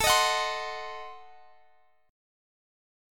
Adim7 Chord
Listen to Adim7 strummed